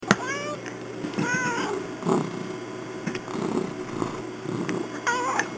* Murdock (the big, fuzzy cat)
[audio] I'm a big hungry cat.
Murdock Ow-oo means "I want to go outside".